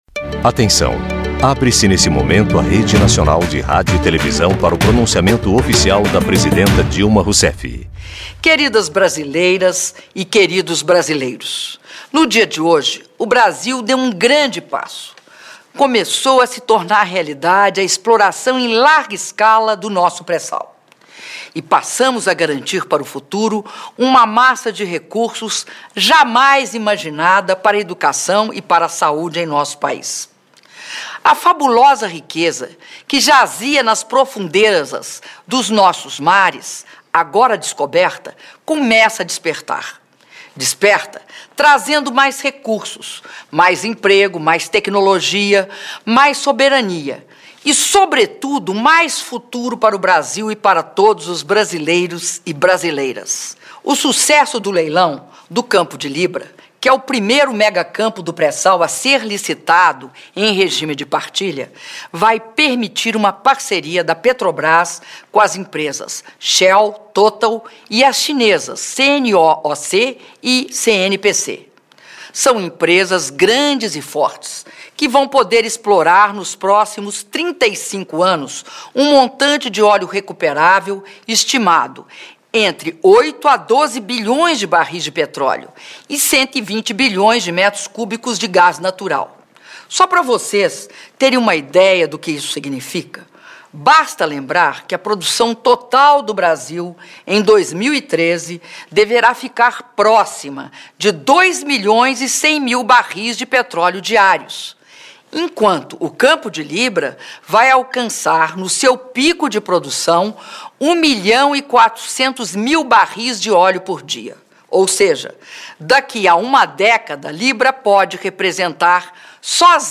Áudio do pronunciamento da Presidenta da República, Dilma Rousseff, em Rede Nacional de Rádio e Televisão, sobre o leilão do Campo de Libra (08min03s)